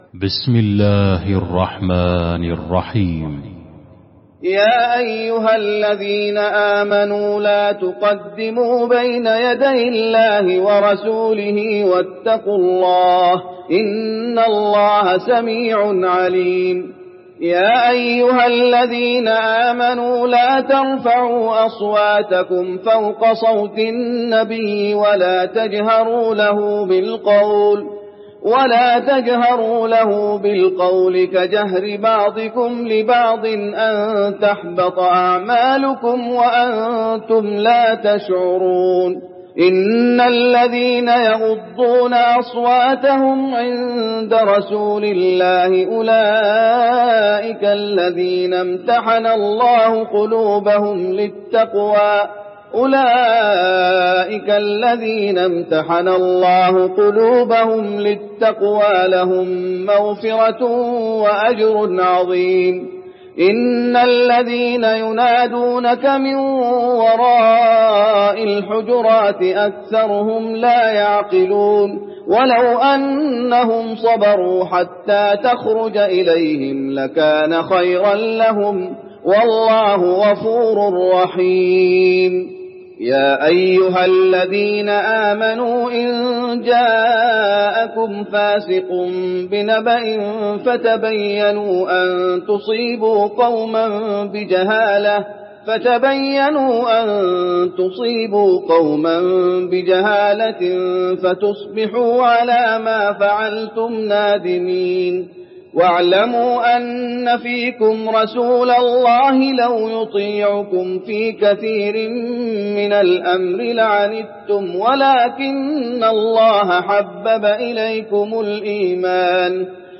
المكان: المسجد النبوي الحجرات